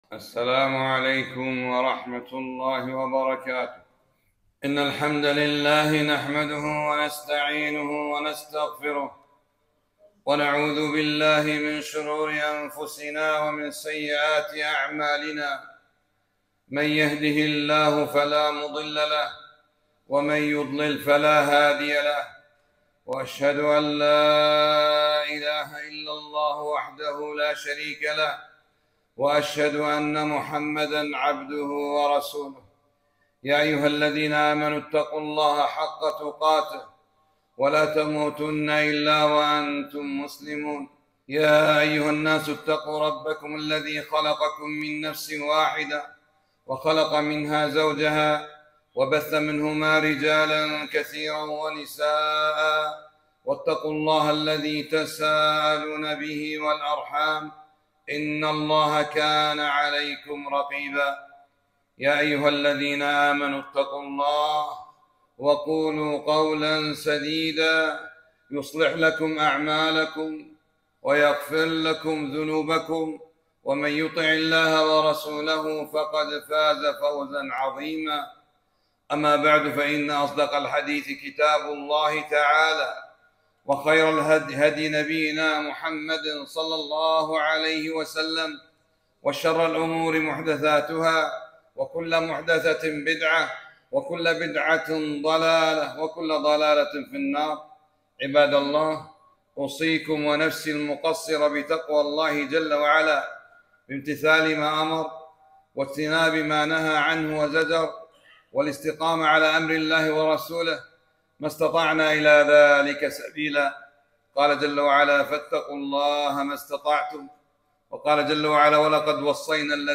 خطبة - الصلاة على النبي ﷺ والإقتداء به